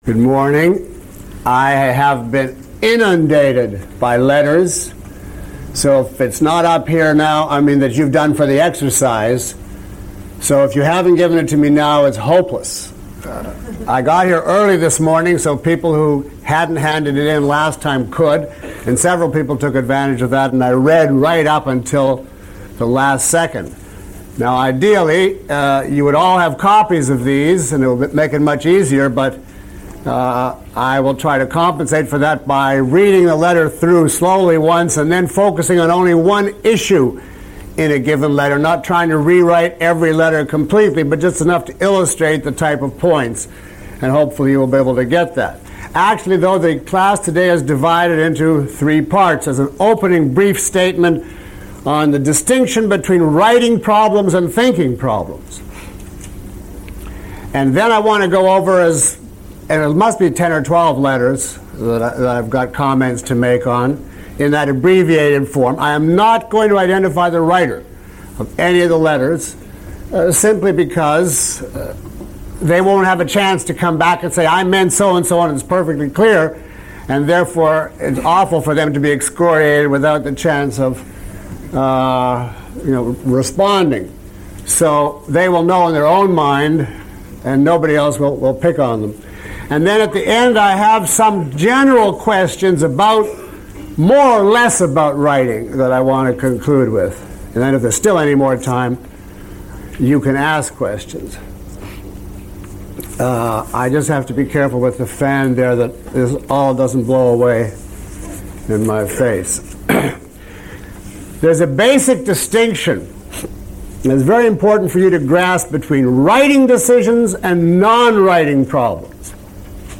Lecture 07 - The Art of Thinking.mp3